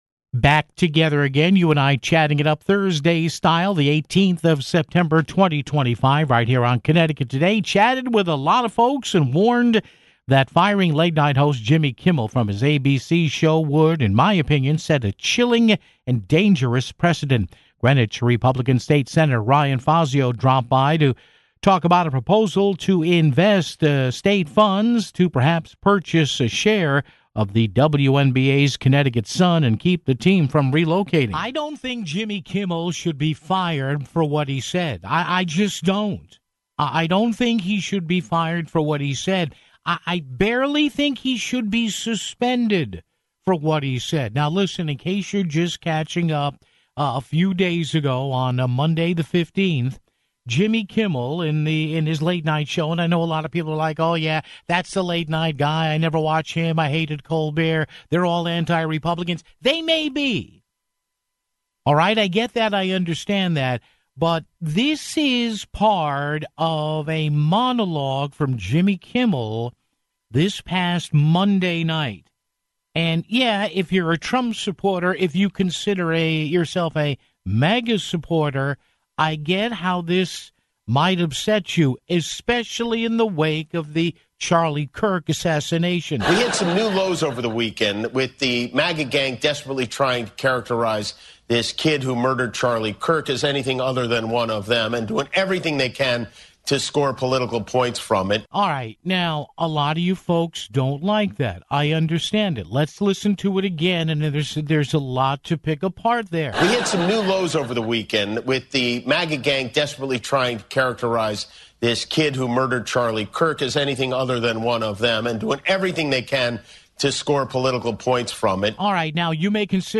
Greenwich GOP State Sen. Ryan Fazio called in to talk about a proposal to invest state funds to purchase a share of the WNBA's Connecticut Sun (15:52)